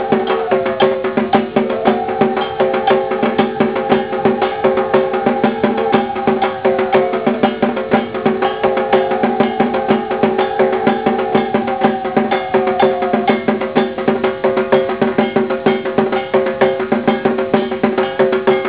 The beat of the dhak (ceremonial drums) [
WAV 146 KB 00:00:18 64 kbps 8 bit Mono 8 kHz]